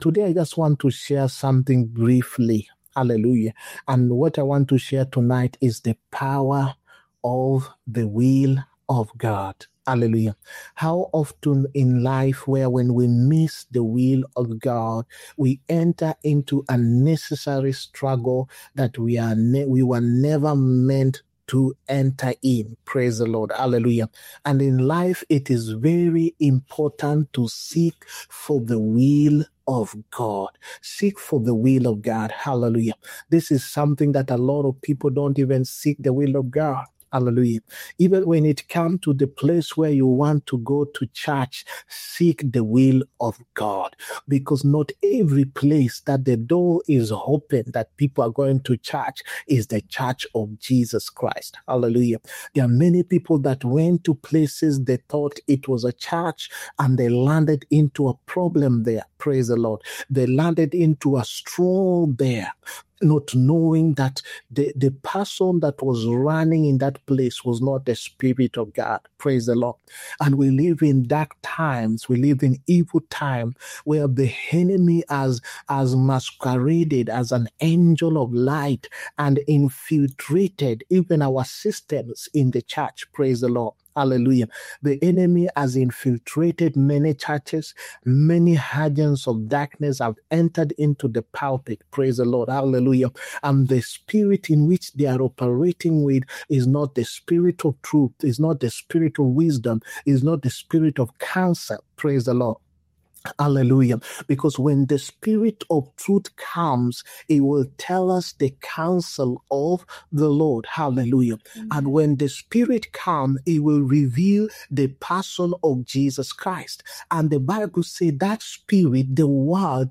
HEALING-PROPHETIC-AND-DELIVERANCE-SERVICE